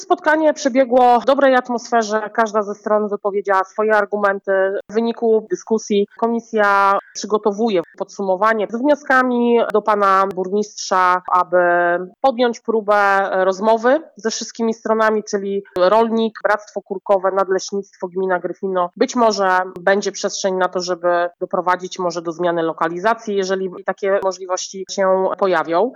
O szczegółach posiedzenia, dla Twojego Radia mówiła radna Magdalena Pieczyńska.